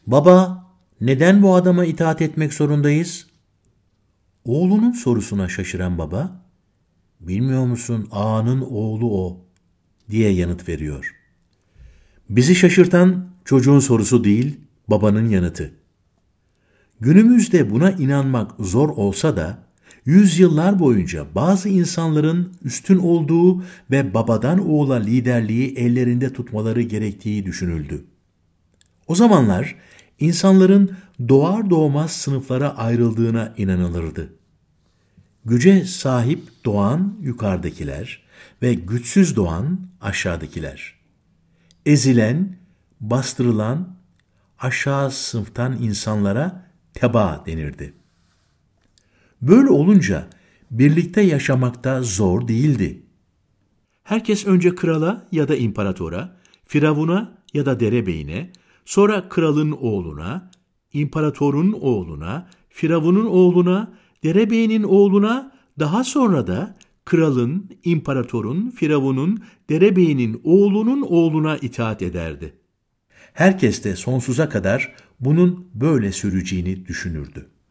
Seslendiren: Altan Erkekli